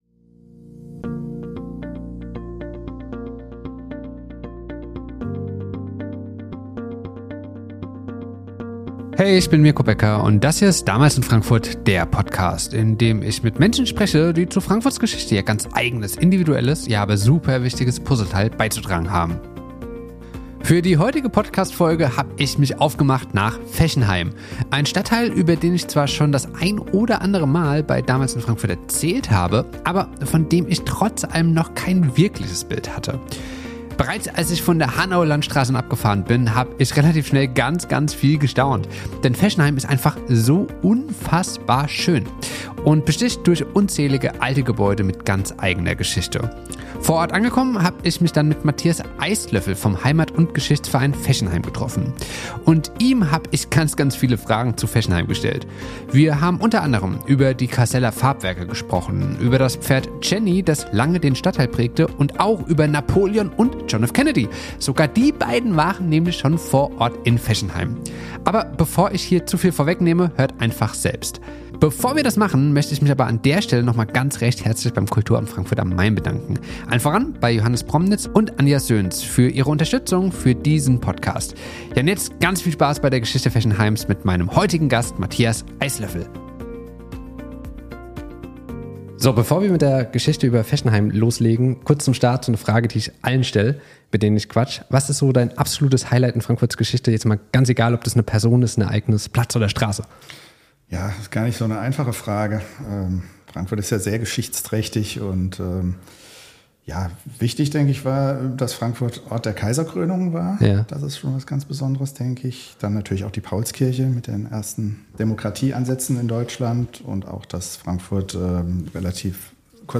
Beschreibung vor 1 Jahr Für die heutige Podcast-Folge habe ich mich aufgemacht nach Fechenheim.